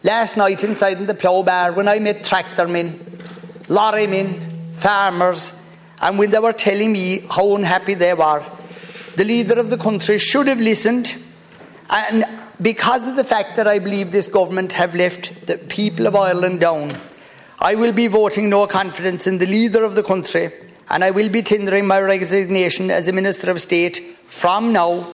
Michael Healy Rae says he asked himself what his father Jackie would have done…………..